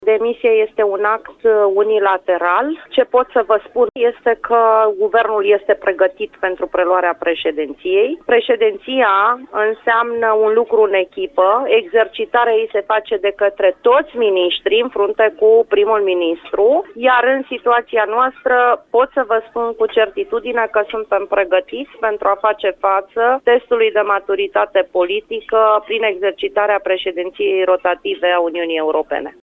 Întrebatã de Radio România Actualitãți cum comenteazã gestul lui Victor Negrescu, ministrul Fondurilor Europene, Rovana Plumb, a rãspuns cã demisia este un act personal și cã România este pregãtitã sã își exercite mandatul la conducerea Uniunii Europene: